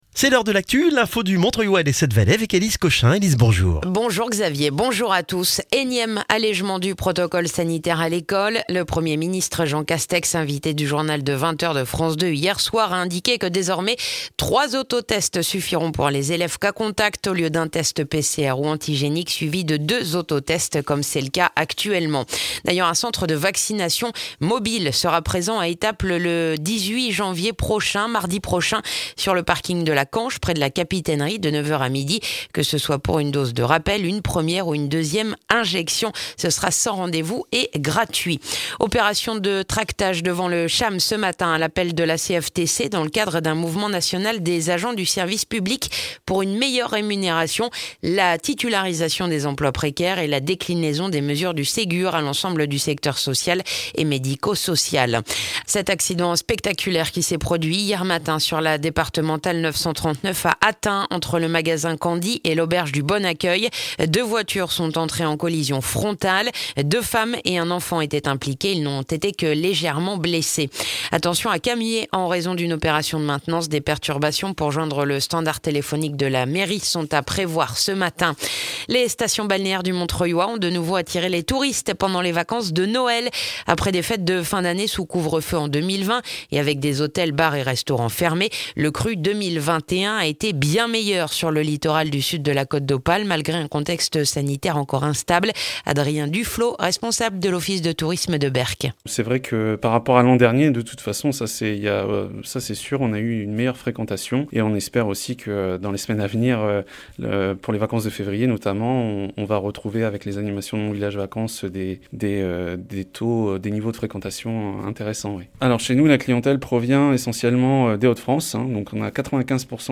Le journal du mardi 11 janvier dans le montreuillois